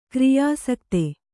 ♪ kriyāsakti